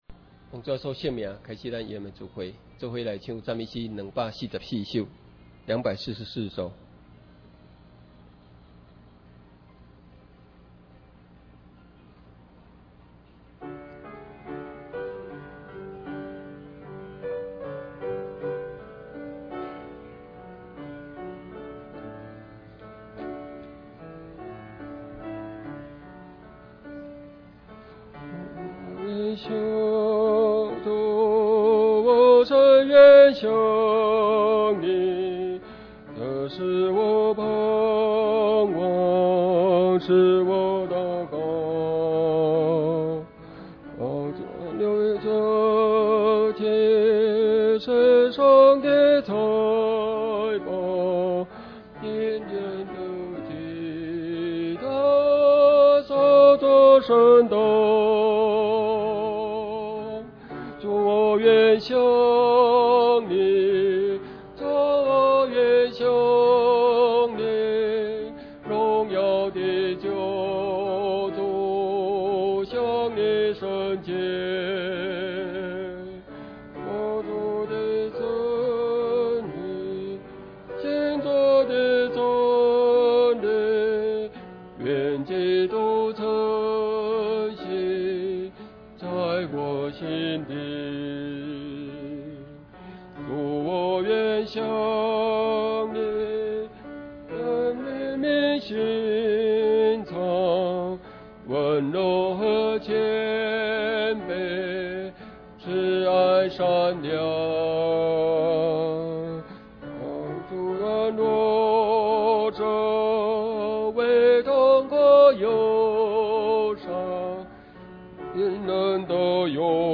聚會錄音檔